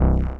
noise5.mp3